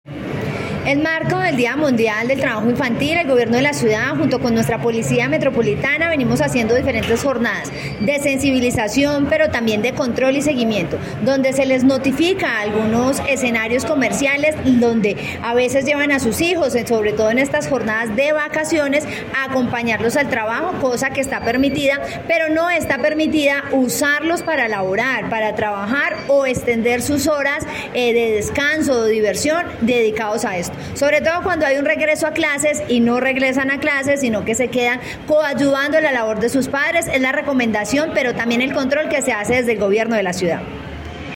Así se pronunció la secretaria de Gobierno de Pereira, Karen Zape Ayala, al participar en una jornada pedagógica llevada a cabo en Plaza de Mercado (Impala), conmemorando el Día Mundial contra el Trabajo Infantil, cuya fecha oficial es 12 de junio de cada año.